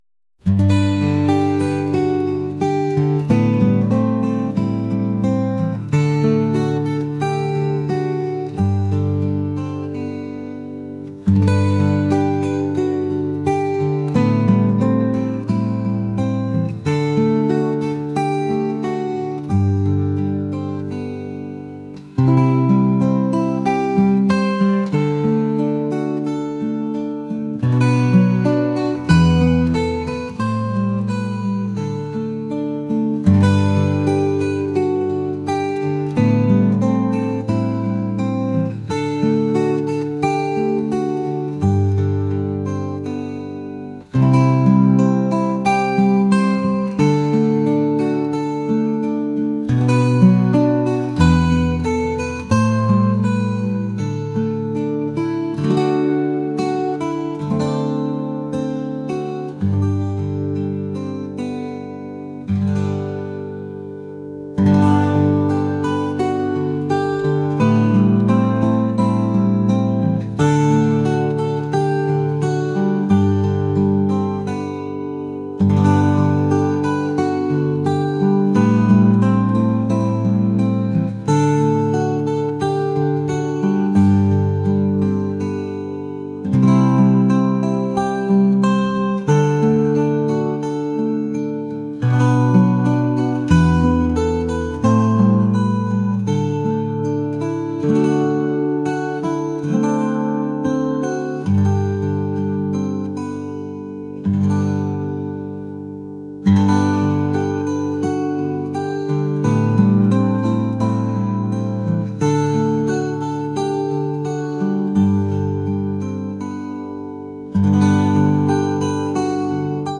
acoustic | folk | ambient